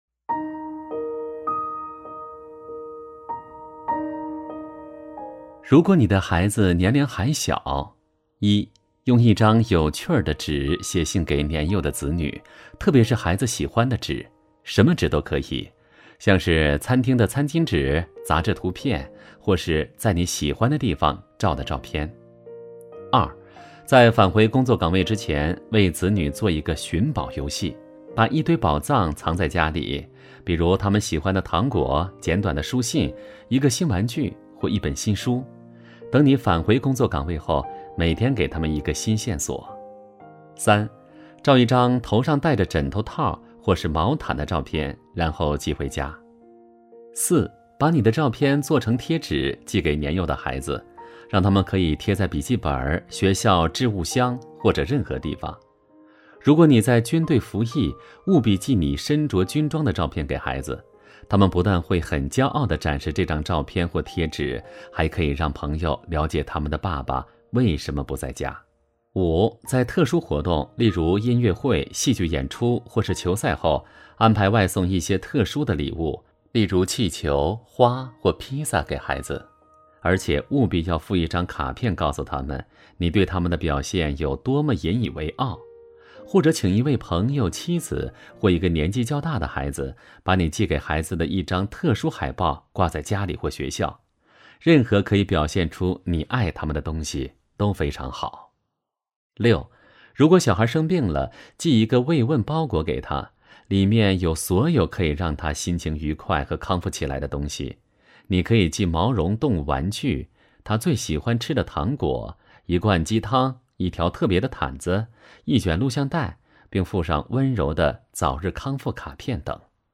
首页 > 有声书 > 婚姻家庭 | 成就好爸爸 | 有声书 > 成就好爸爸：40 如果你的孩子年龄还小